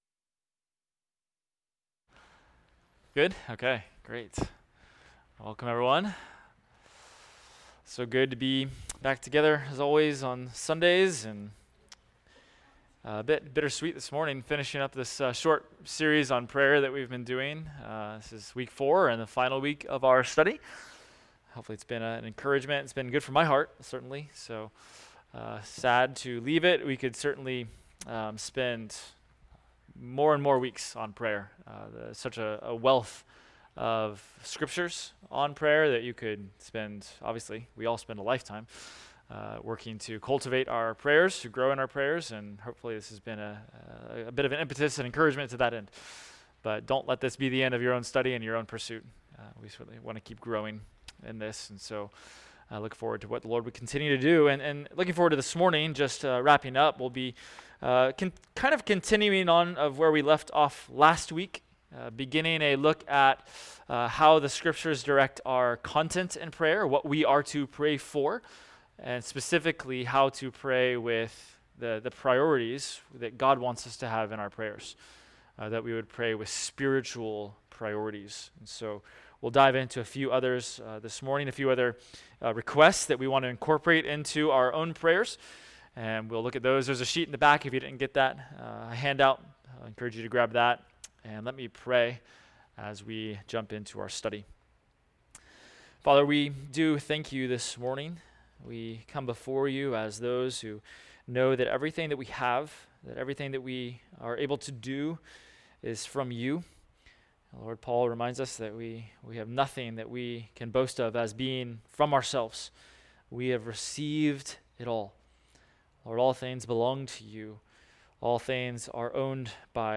Adult Sunday School – Prayers of Scripture – Week 4